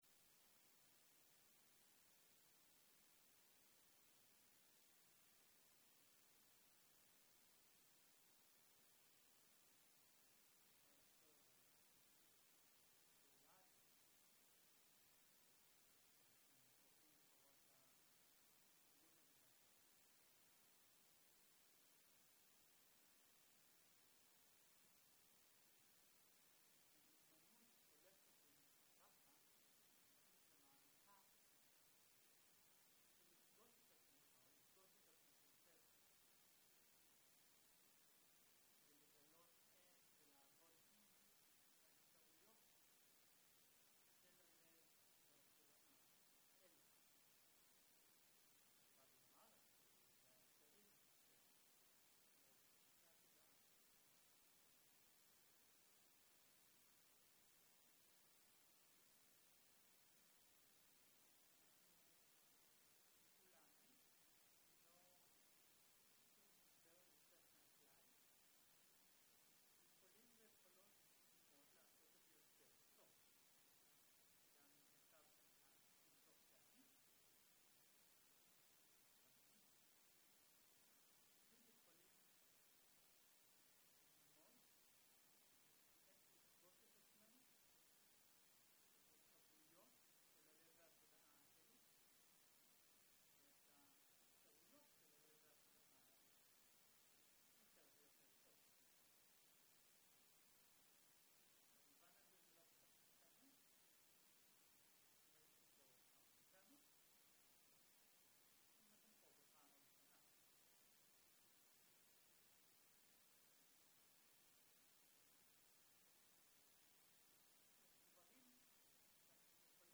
08.02.2023 - יום 1 - ערב - מדיטציה מונחית - הקלטה 1
Dharma type: Guided meditation שפת ההקלטה